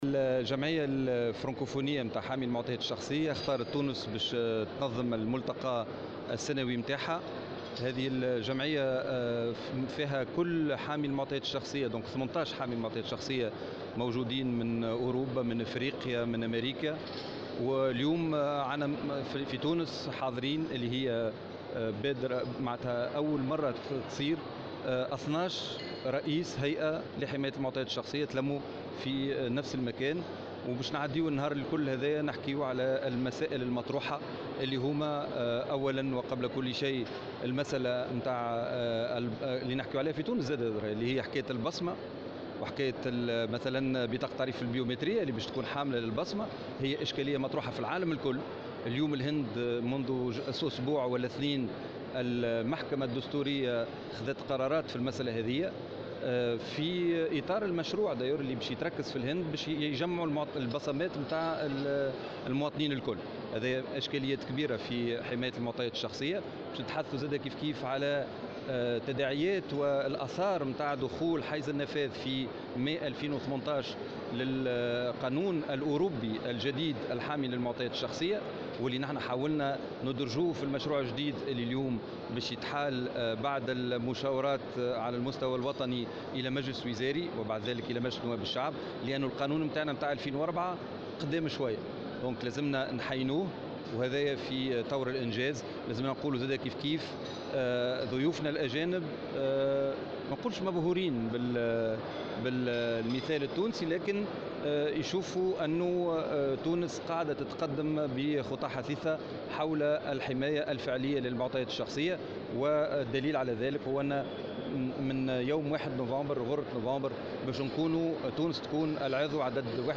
وأوضح في تصريحات صحفية على هامش ملتقى فرنكوفوني في تونس العاصمة لهيئات حماية المعطيات الشّخصية، واكبته مراسلة "الجوهرة أف أم"، أنه تم رفع "شكايات" ضد خمس مصحات خاصة بسبب استخدامها بصمات المواطنين وتخزينها في معاملاتها.